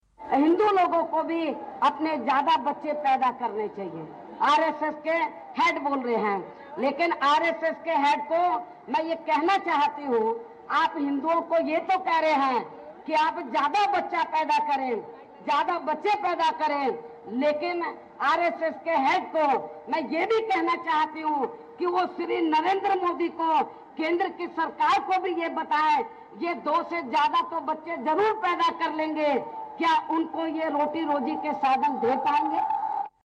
आगरा में हुई एक रैली में बहुजन समाज पार्टी की प्रमुख मायावती ने आरएसएस प्रमुख मोहन भागवत पर निशाना साधा. उन्होंने कहा कि आरएसएस प्रमुख हिंदुओं को दो से अधिक बच्चे पैदा करने के लिए तो कह रहे हैं लेकिन क्या उन्होंने केंद्र में मोदी सरकार से कहा कि वे इन बच्चों के रोज़गार की व्यवस्था कैसे करेंगे.